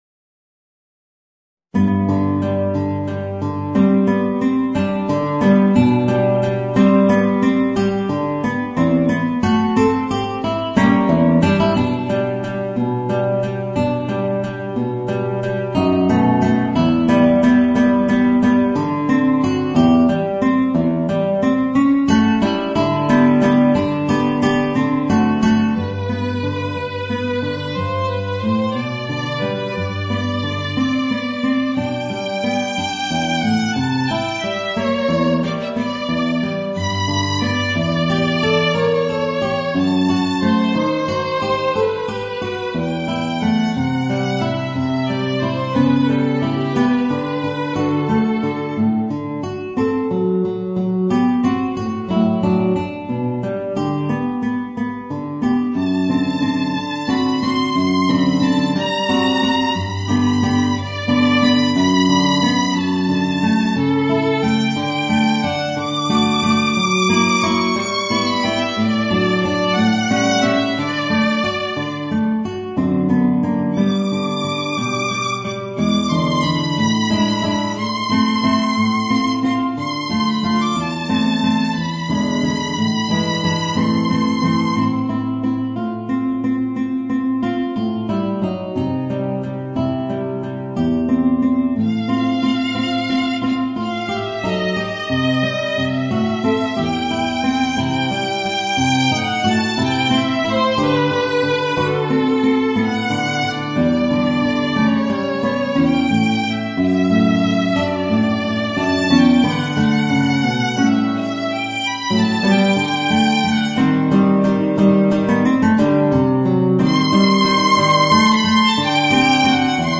Voicing: Guitar and Violin